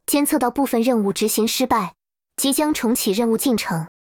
maa_partial_task_failed.wav